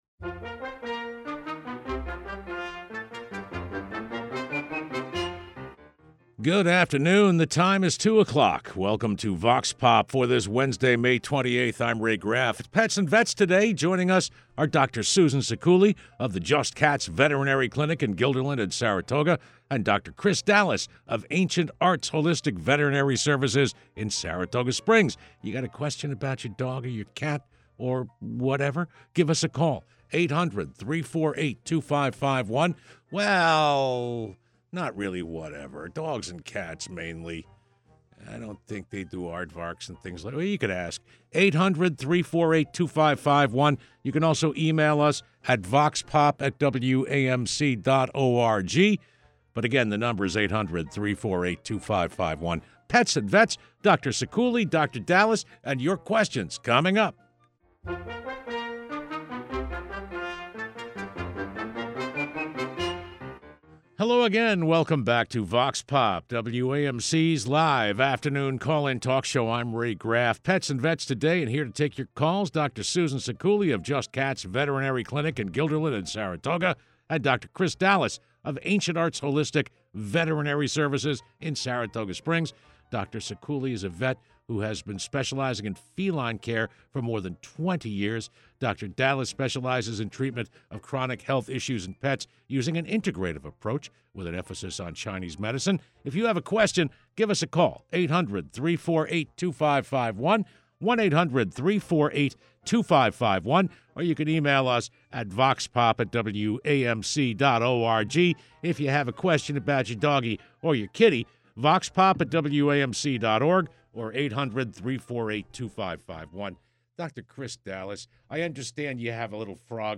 Vox Pop is WAMC's live call-in talk program.
Our experts take questions posed by WAMC listeners.